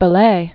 (bə-lā, bĕ-lā), Joachim du 1522?-1560.